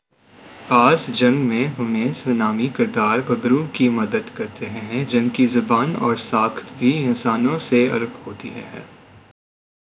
deepfake_detection_dataset_urdu / Spoofed_TTS /Speaker_07 /104.wav